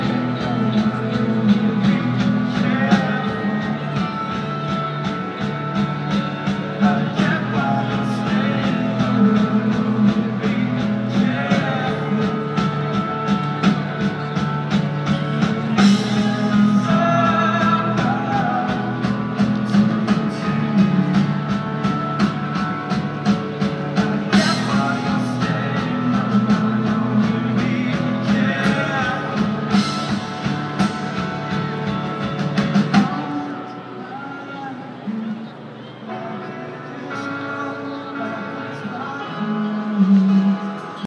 Live music at the Gold Coast Suns 5th Quarter Bar
Live band performs before the match in the Fifth Quarter Bar